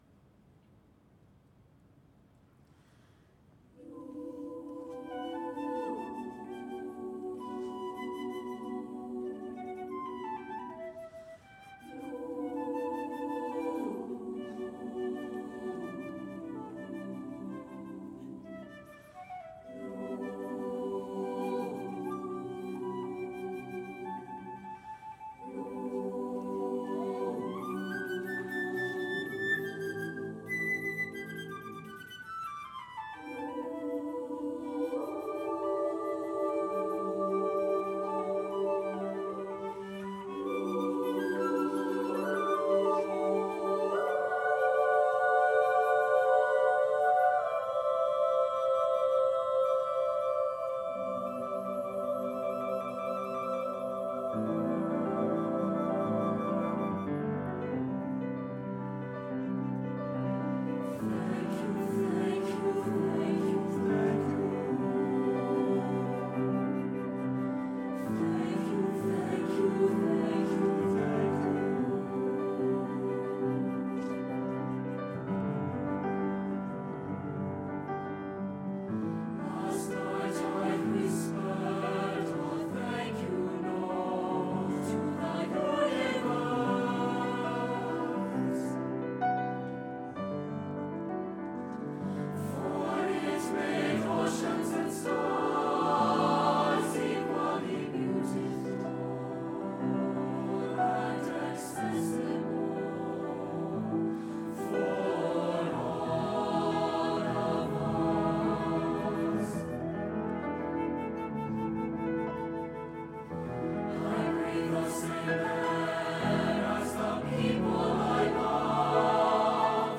A powerful and colorful affirmation of life’s beauty.
SATB, flute, and piano